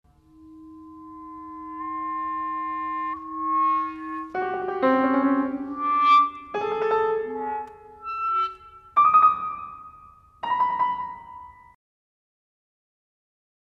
for clarinet and piano